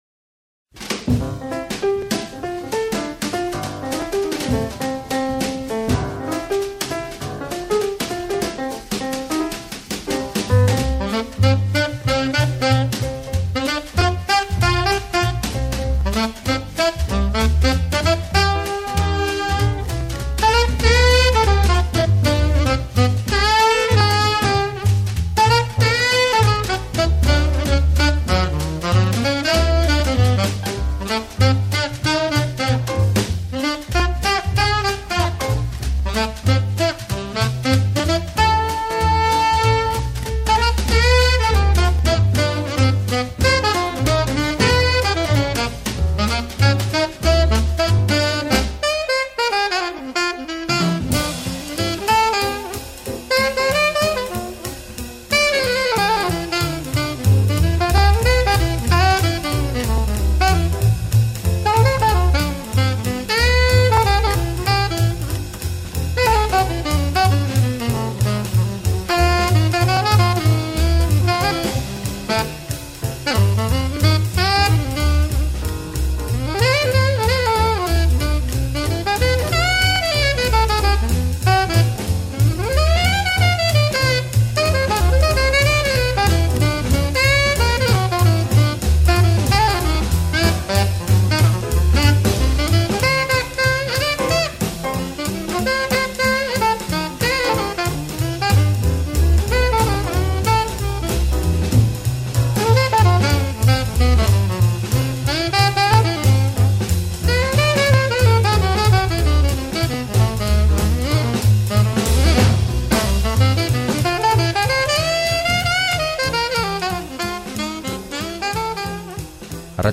BIGARREN LERROAN: gitarra jotzaileak entzungai